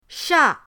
sha4.mp3